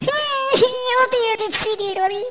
1 channel
SND_GAMEOVER.mp3